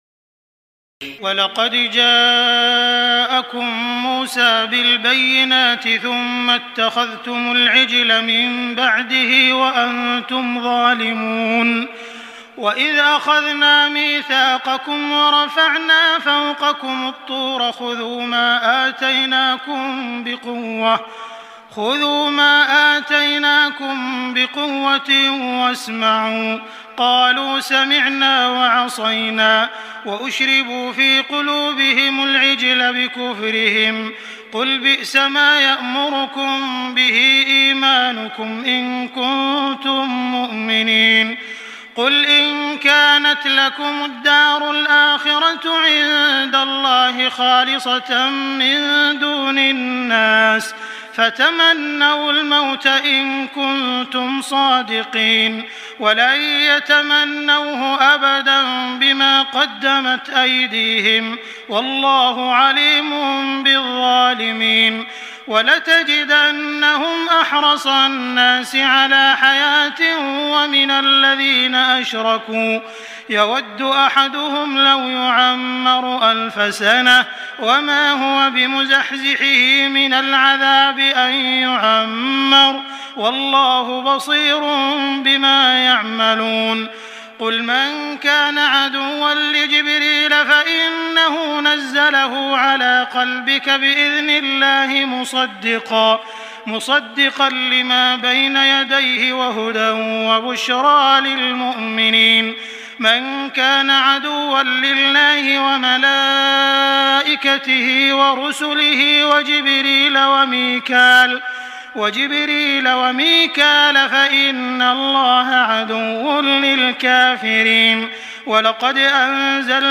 تهجد ليلة 21 رمضان 1422هـ من سورة البقرة (92-141) Tahajjud 21 st night Ramadan 1422H from Surah Al-Baqara > تراويح الحرم المكي عام 1422 🕋 > التراويح - تلاوات الحرمين